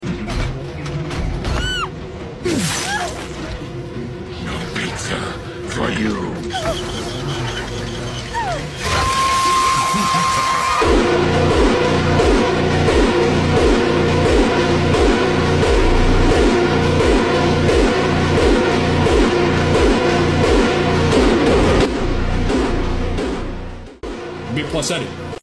super slowed